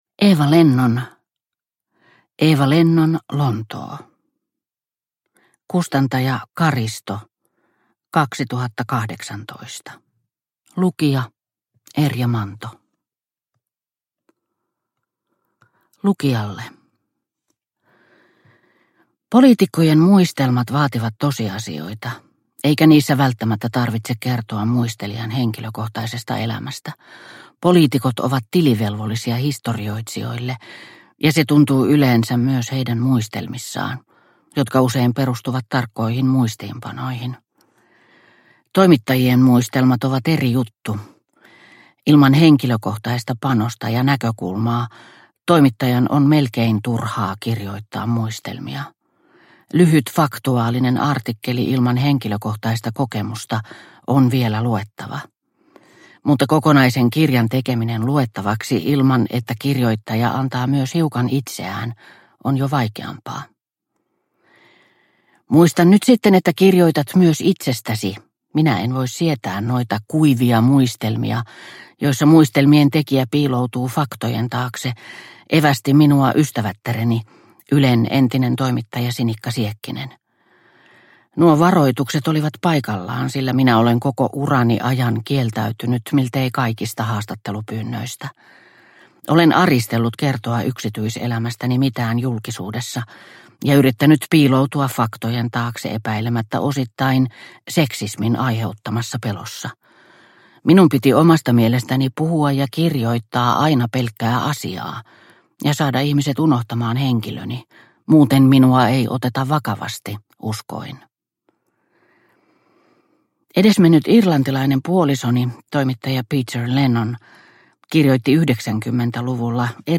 Eeva Lennon, Lontoo – Ljudbok – Laddas ner